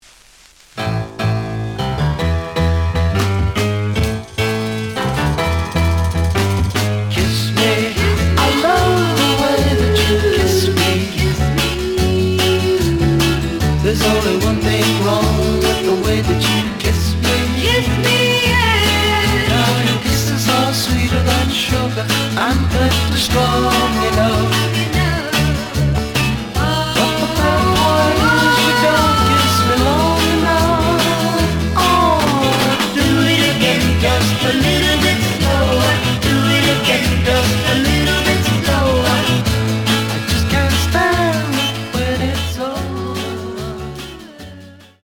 The audio sample is recorded from the actual item.
●Genre: Rock / Pop
Looks good, but slight noise on both sides.)